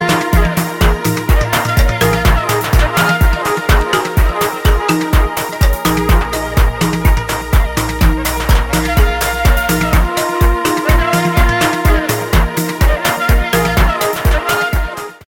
[AFRO&LATIN]